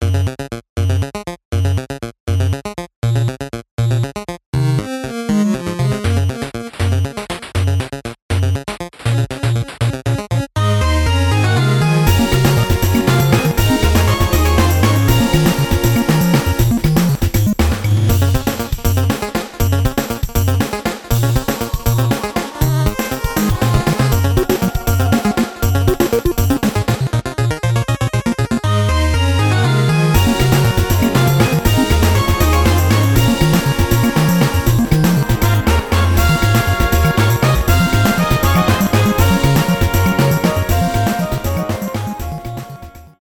8 бит , без слов
электронные